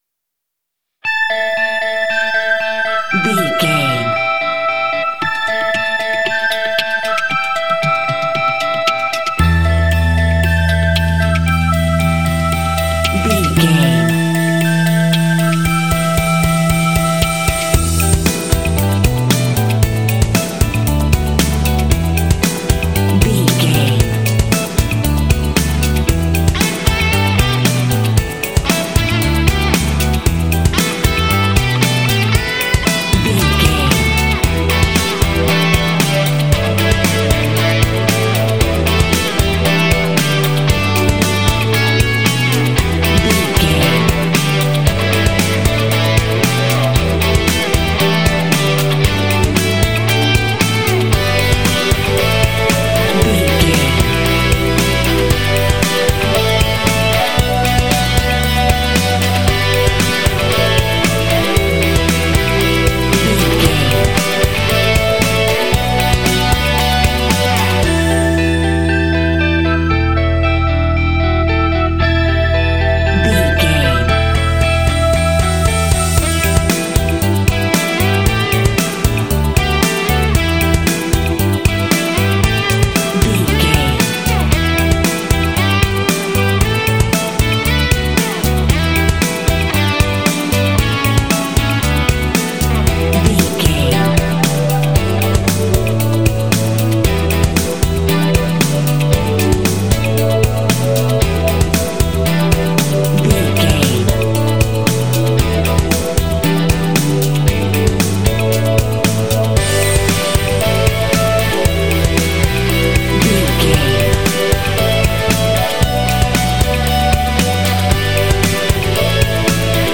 Dorian
cool
happy
groovy
bright
electric guitar
strings
bass guitar
synthesiser
percussion
alternative rock
symphonic rock